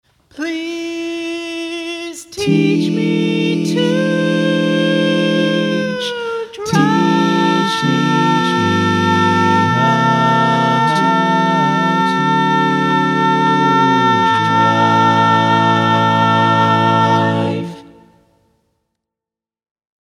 Key written in: A Major
How many parts: 4
Type: Barbershop
All Parts mix:
Learning tracks sung by